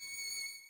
Paradise/sound/violin/Db7.ogg at 355666e1a825252a4d08fa4e5cfced85e107ce39
Db7.ogg